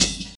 Closed Hats
Hat_ride.wav